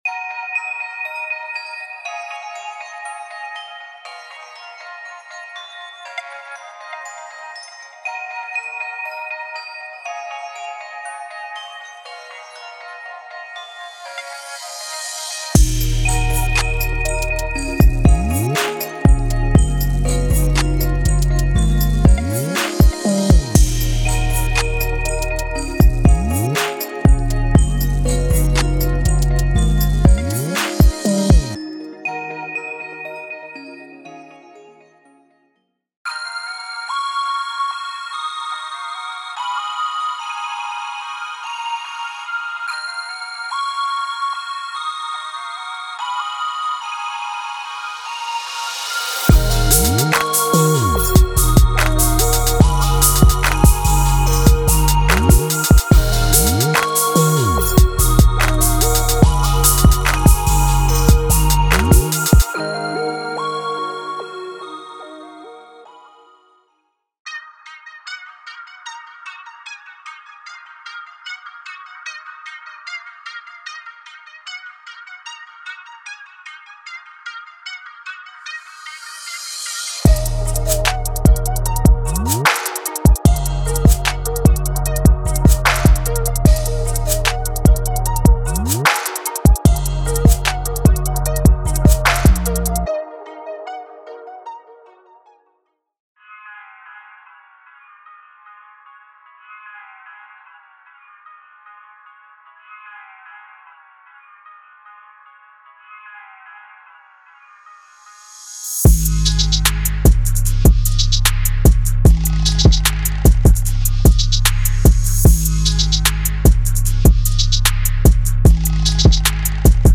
从吉他和弦乐器到长笛和木管乐器，这个包可以让您充分享受。
施工套件随附演示节拍，WAV + MIDI导出和样本！
•完全掌握了混音